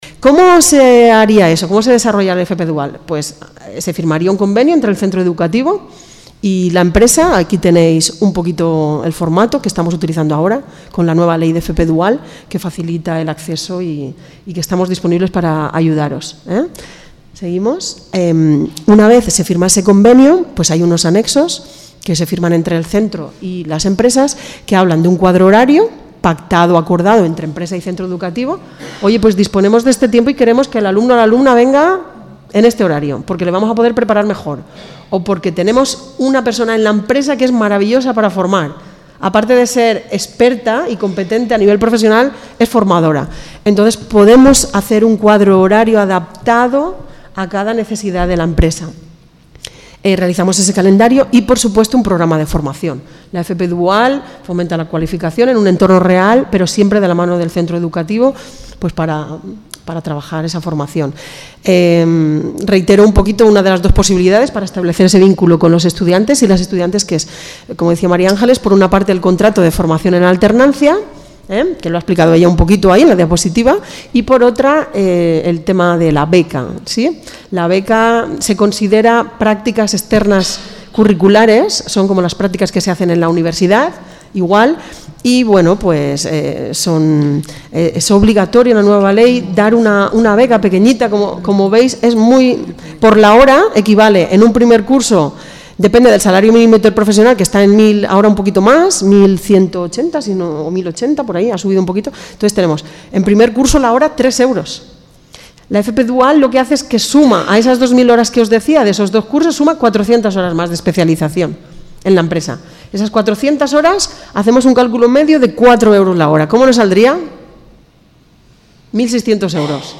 Interesante charla debate sobre empleabilidad y formación del sector del calzado